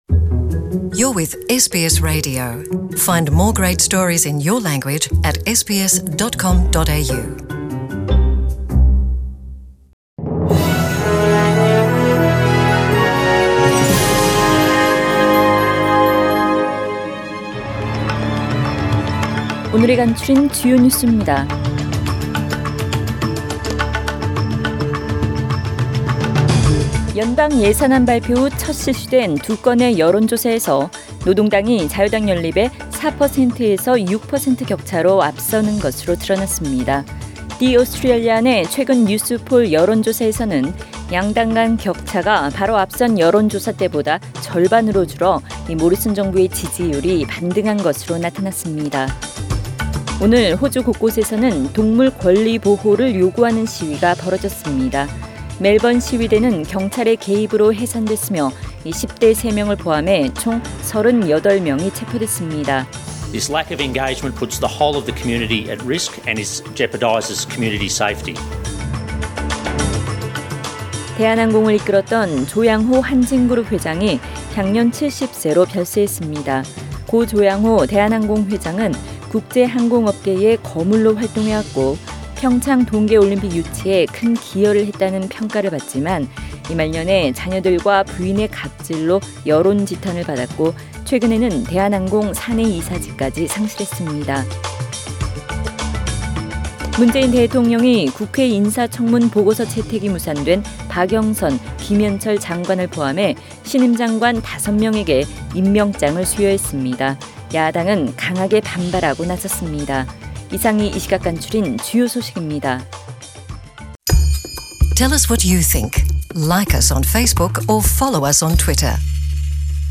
2019년 4월 8일 월요일 저녁의 SBS Radio 한국어 뉴스 간추린 주요 소식을 팟 캐스트를 통해 접하시기 바랍니다.